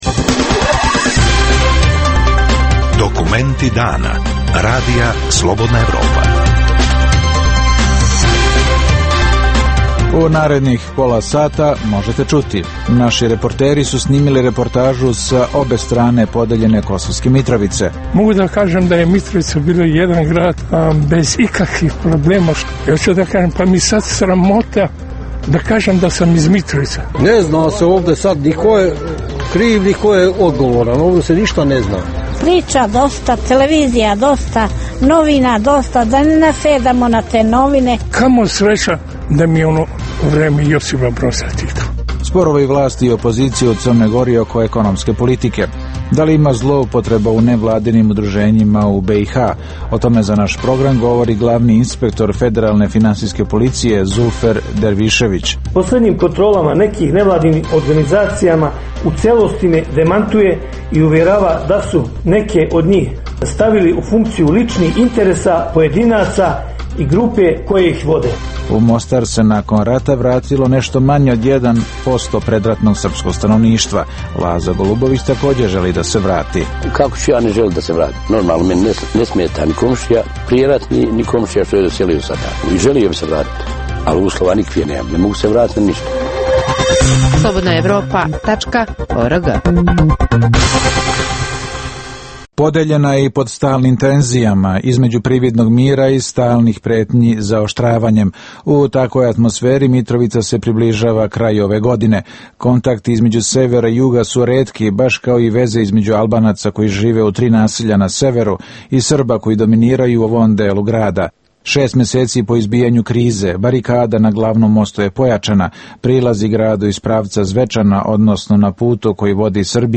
- Reportaža sa obe strane podeljene Mitrovice. - Sporovi vlasti i opozicije u Crnoj Gori oko ekonomske politike. - Glavni inspektor federalne finansijske policije Zulfer Dervišević govori o zloupotrebama u nevladinim udruženjima u BiH.